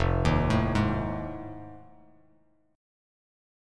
F7#9 chord